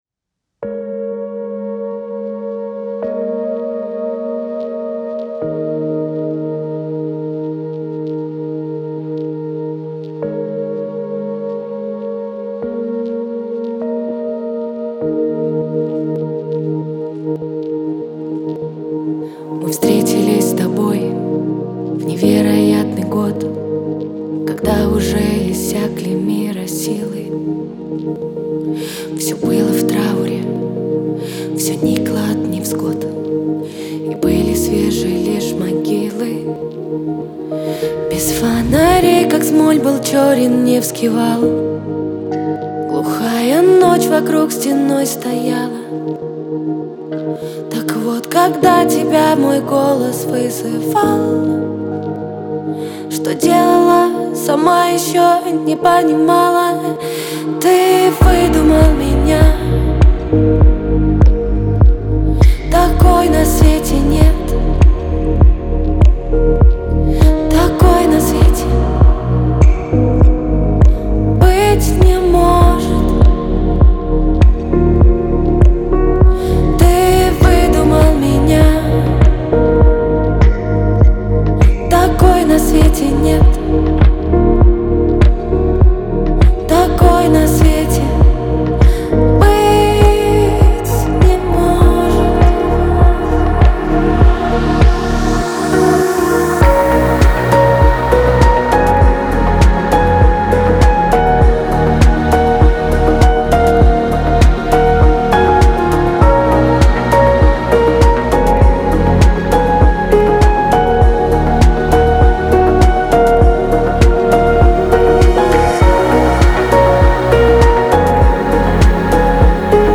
эмоциональная поп-баллада